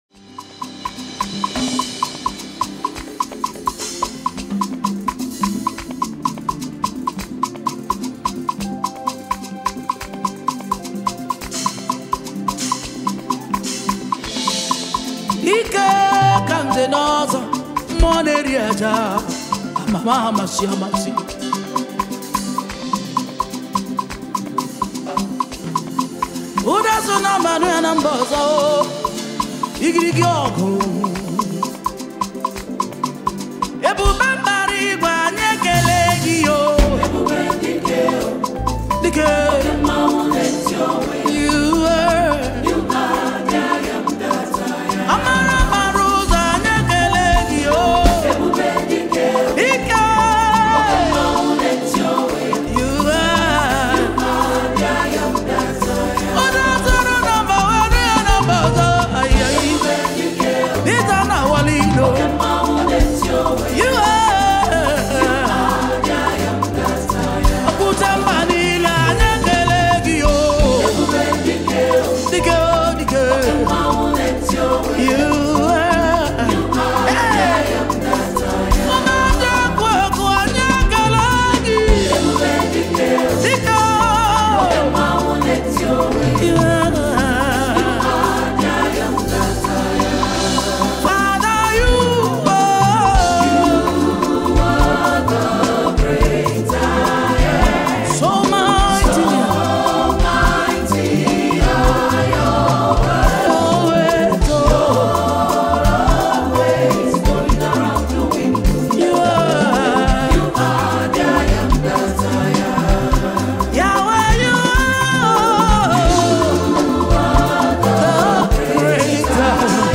powerful worship song
and high-quality audio